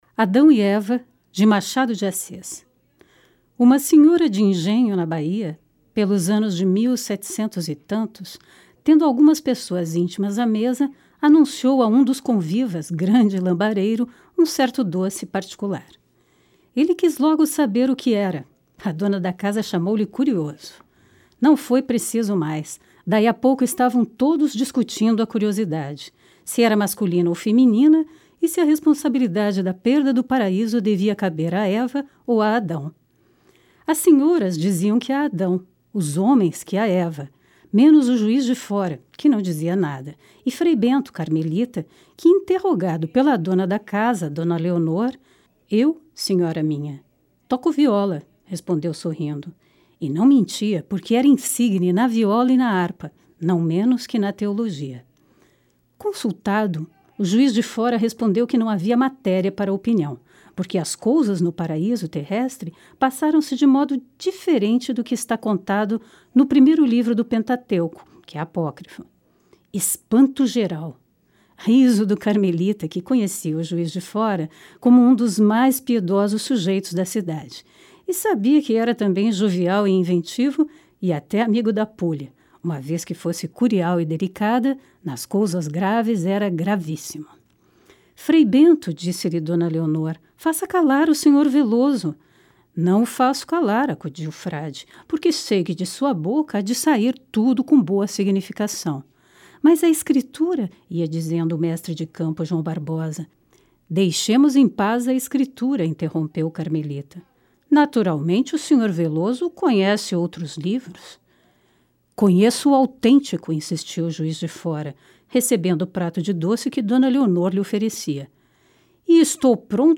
Leitura do conto Adao e Eva de Machado de Assis
Leitura expressiva de contos de Machado de Assis